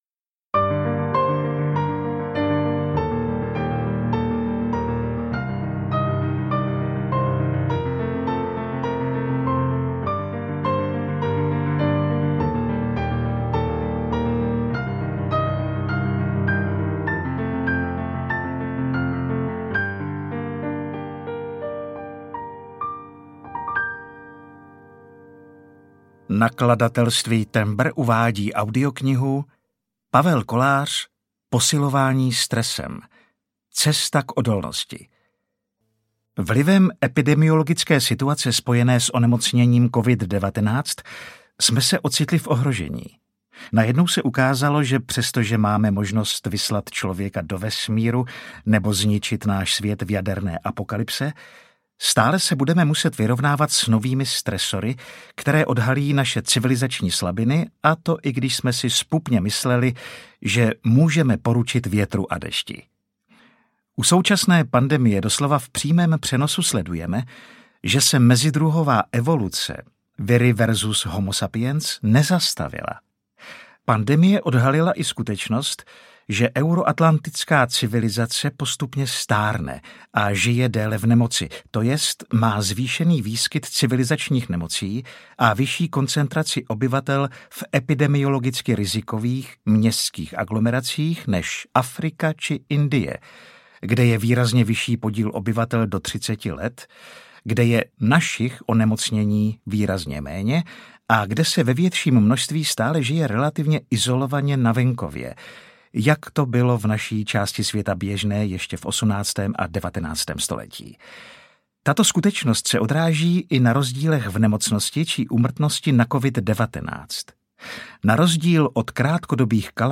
Ukázka z knihy
• InterpretAleš Procházka, Zuzana Slavíková